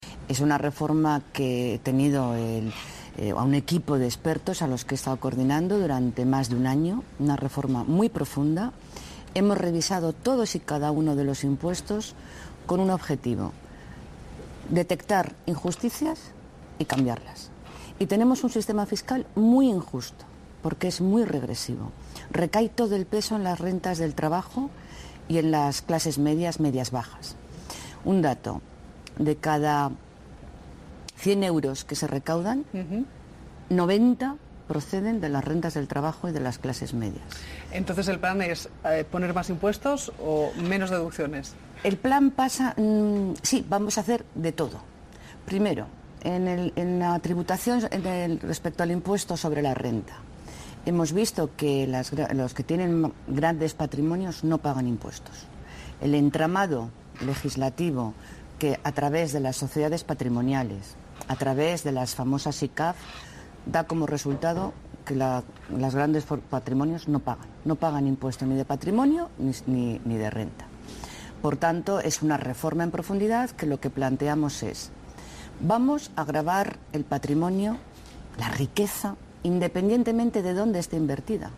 Inmaculada Rodríguez Pinero. Entrevista en los Desayunos de TVE. 15/04/2013